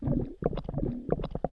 foot.wav